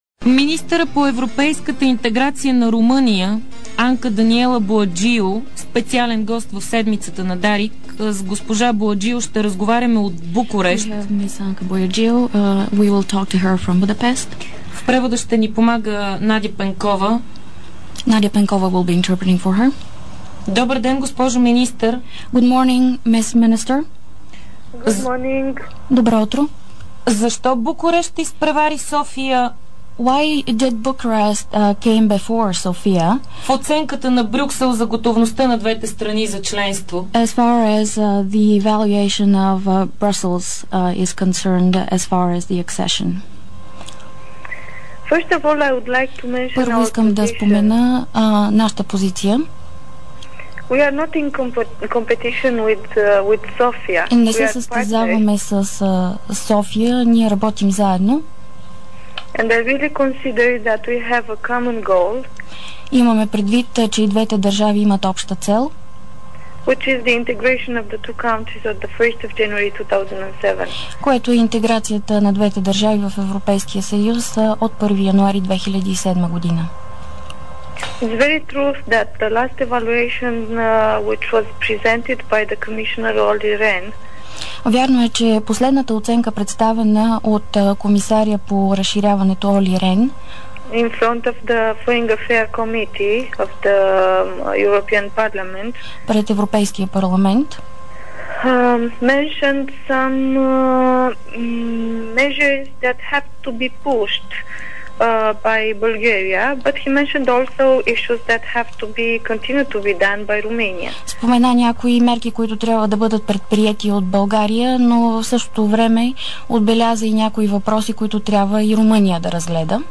Анка Боаджиу, министър по европейската интеграция на Румъния